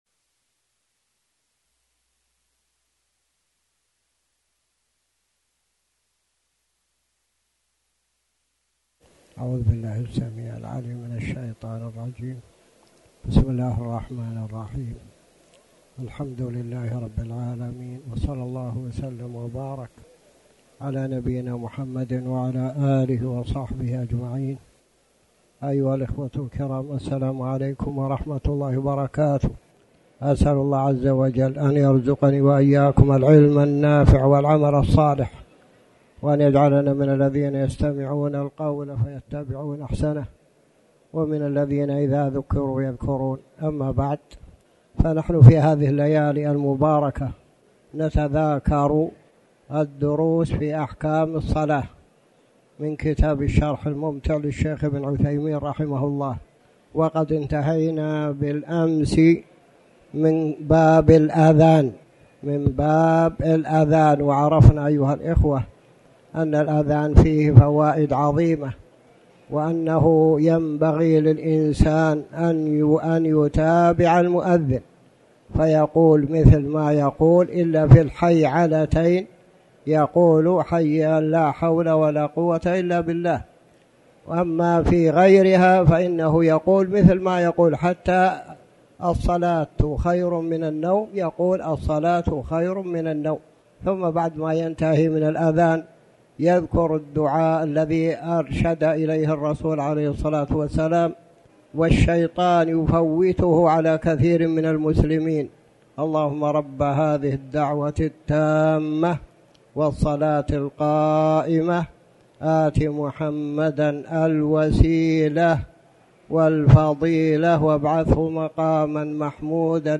تاريخ النشر ٢٠ صفر ١٤٤٠ هـ المكان: المسجد الحرام الشيخ